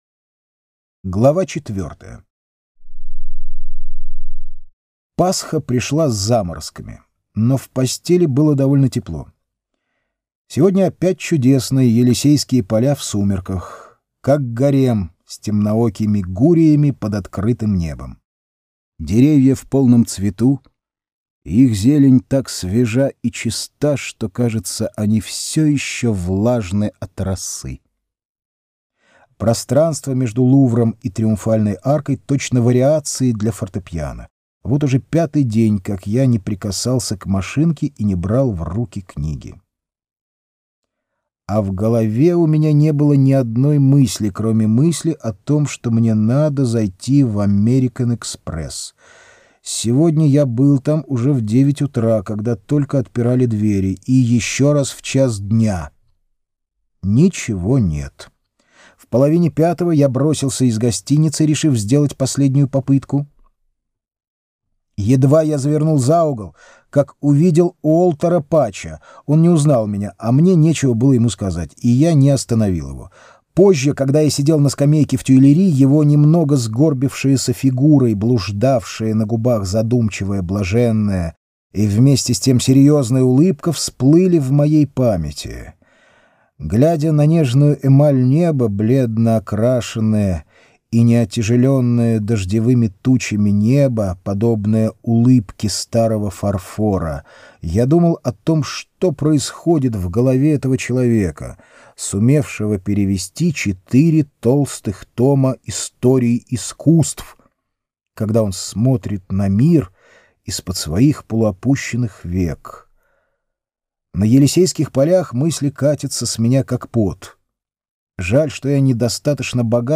Аудиокнига: Тропик Рака